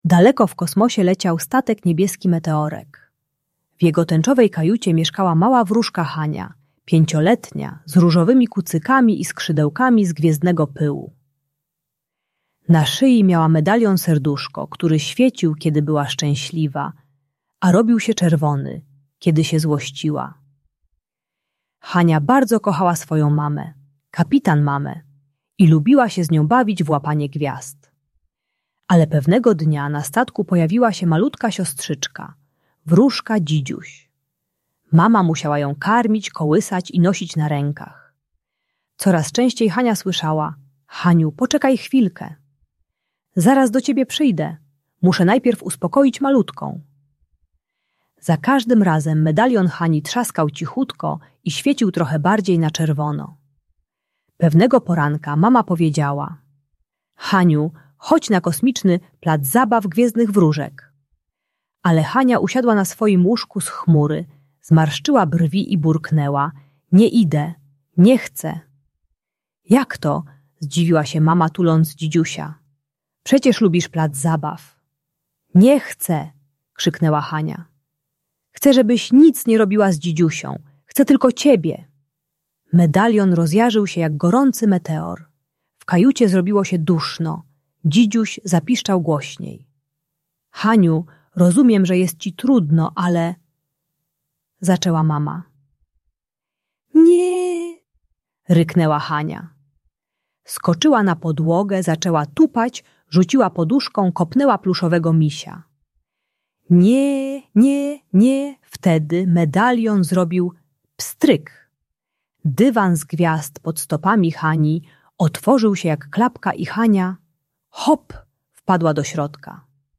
Kosmiczna opowieść o wróżce Hani - Rodzeństwo | Audiobajka